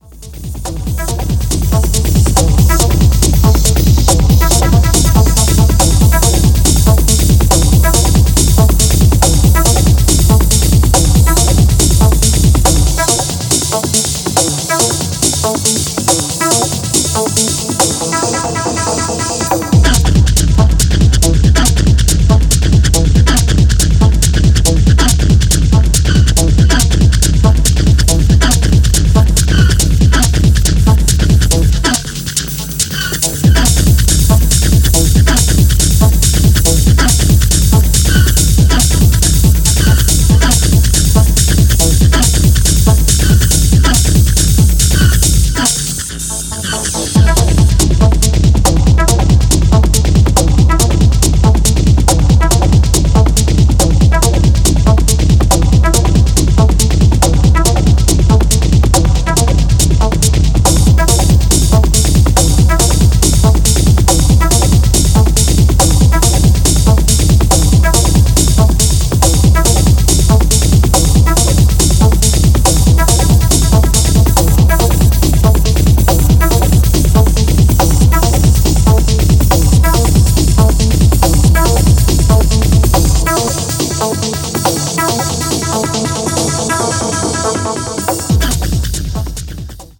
Techno planted for the floor and always going somewhere.